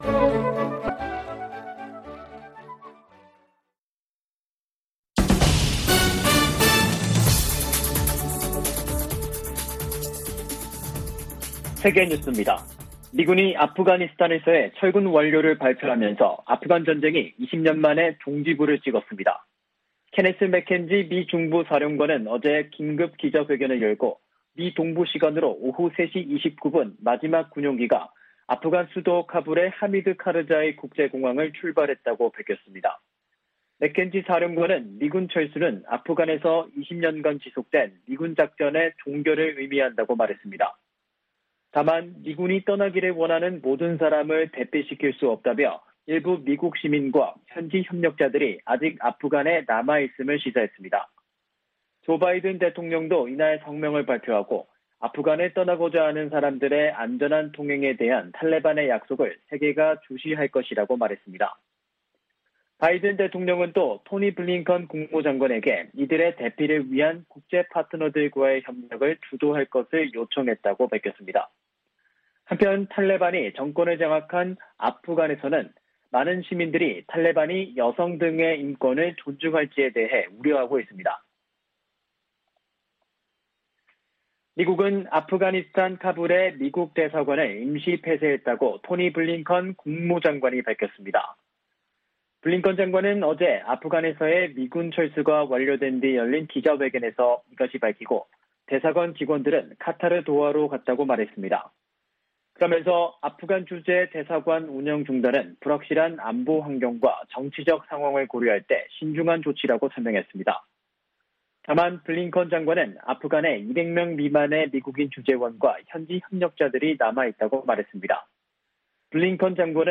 VOA 한국어 간판 뉴스 프로그램 '뉴스 투데이', 2021년 8월 31일 3부 방송입니다. 미국 정부는 북한 영변 핵 시설의 원자로 재가동 정황을 포착했다는 국제원자력기구 보고서에 대해 대화와 외교를 강조했습니다. 미국의 전직 핵 협상가들은 북한이 영변 원자로 재가동을 대미 압박과 협상의 지렛대로 이용할 수 있다고 분석했습니다. 미국 하원 군사위원회의 2022회계연도 국방수권법안에 4년 만에 처음으로 주한미군 감축을 제한하는 조항이 포함되지 않았습니다.